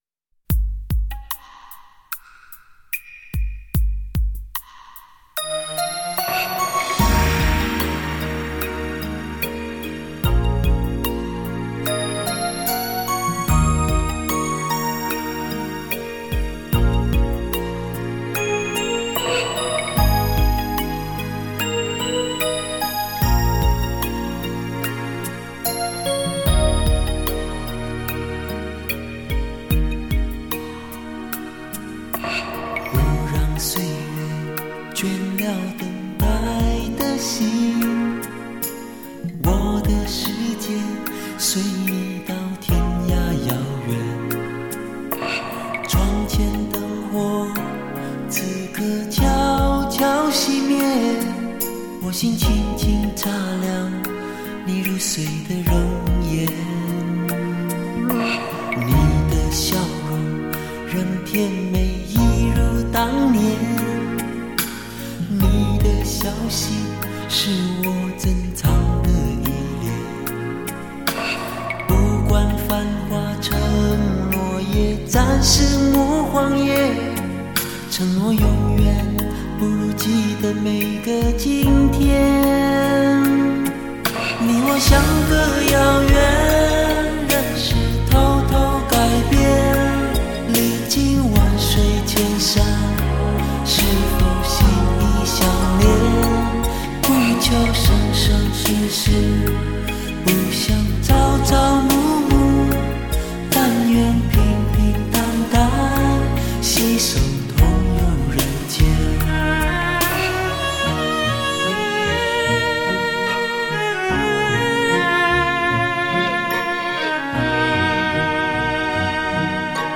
抒情摇滚武侠